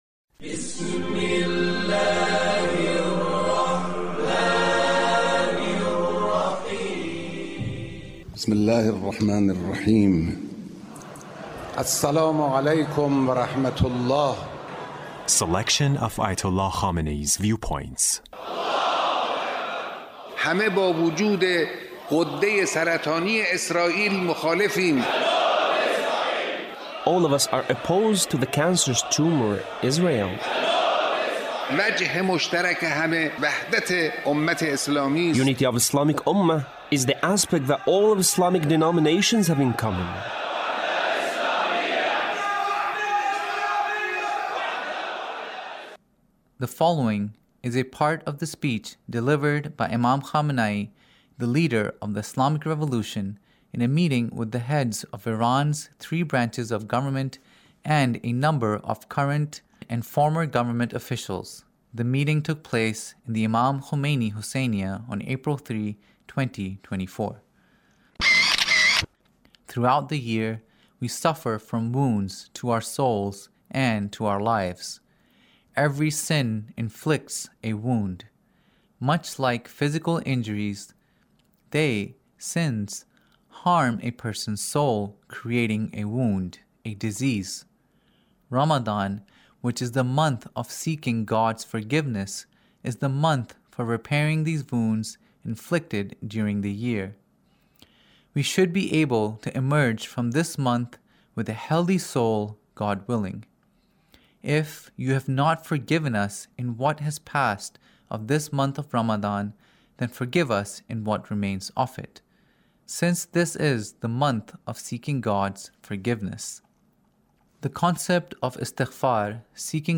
Leader's Speech in a Meeting with the Three Branches of Government Spirituality